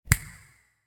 snap.ogg